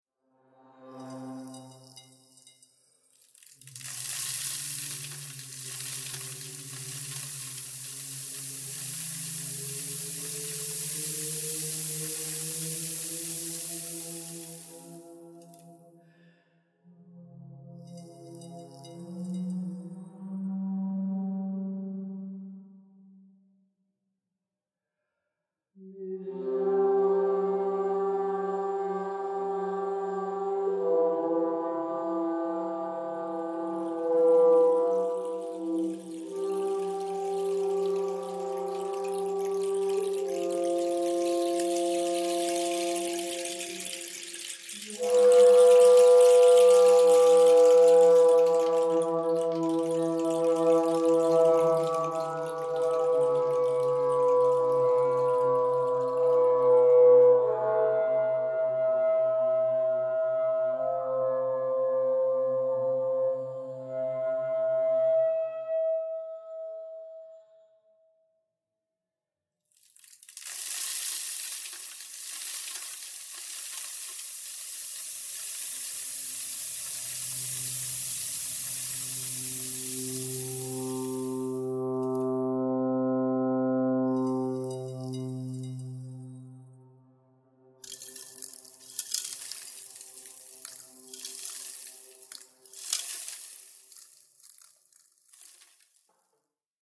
Stift St. Peter, Salzburg.